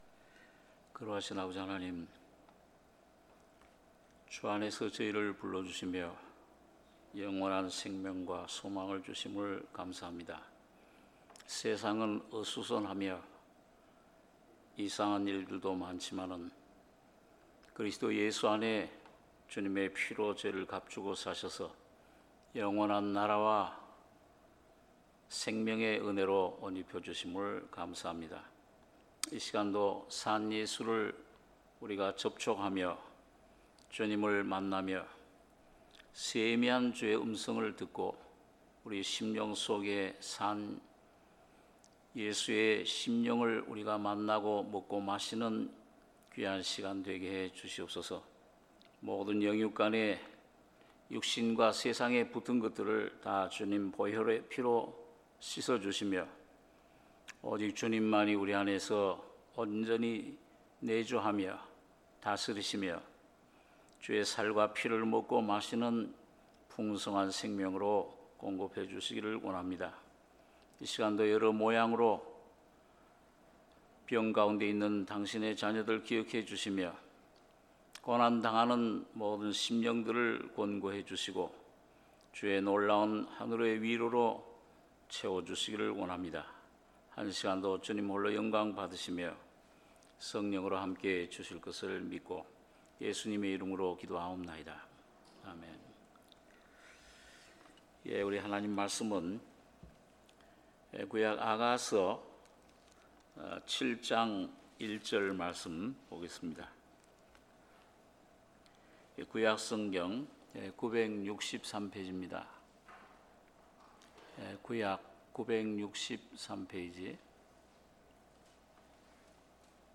수요예배 아가서 7장 1~10절